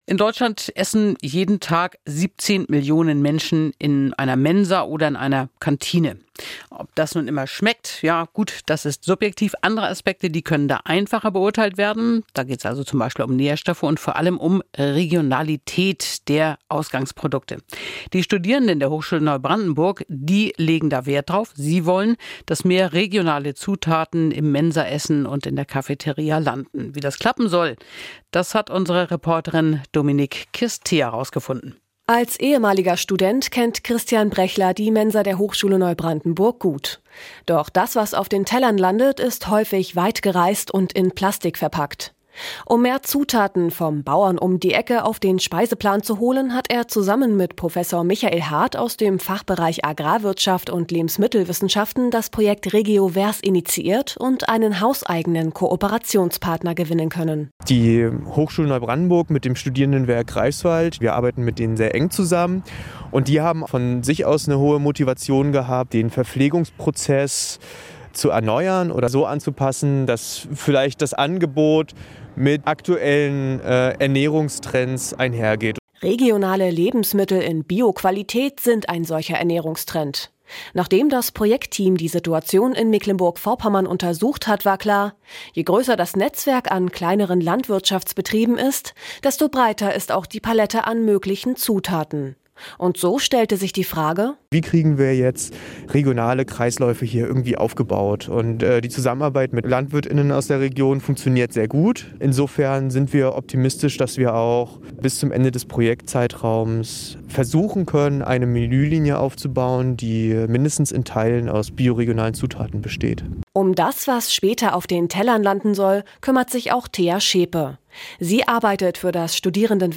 RegioVers im Radio